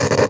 radio_tv_electronic_static_17.wav